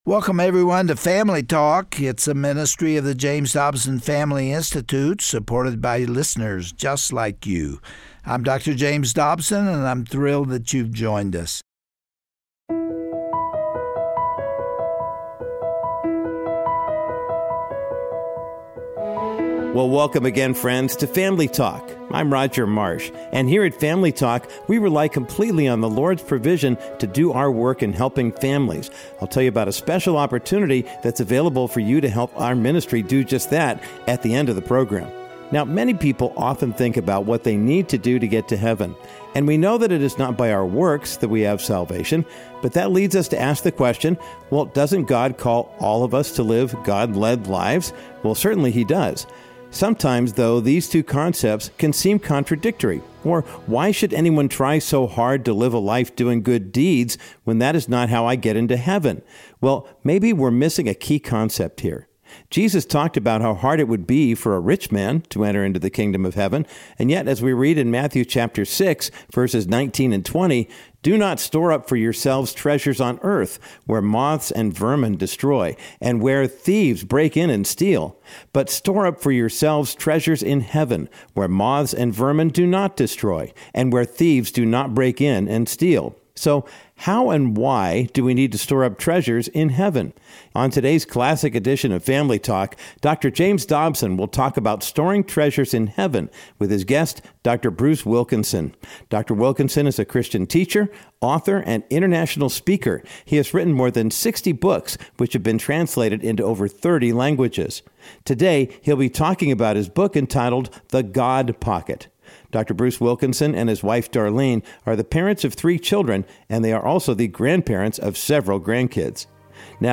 But what do his commands mean for your family budget? Dr. Bruce Wilkinson joins Dr. Dobson to discuss his new book which will leave you thinking for days.